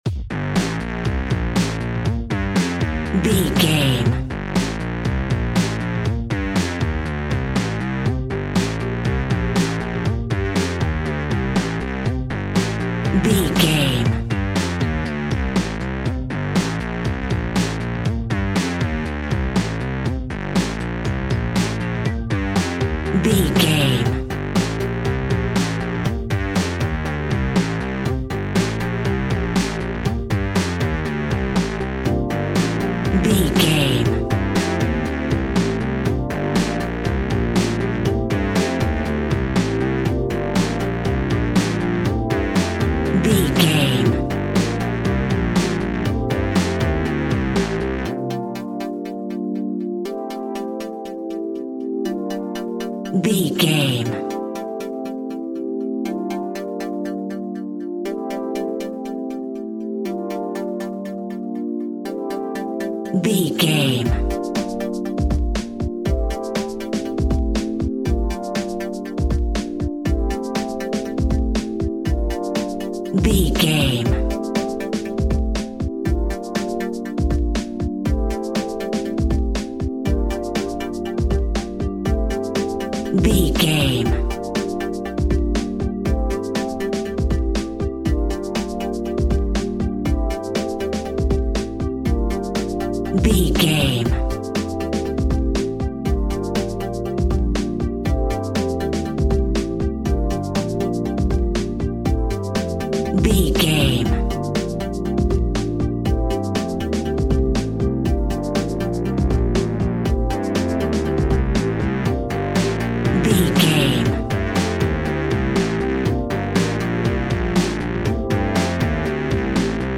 80s Cop Film Music.
Aeolian/Minor
driving
uplifting
lively
hypnotic
industrial
drum machine
synthesiser
electronic
techno
bass synth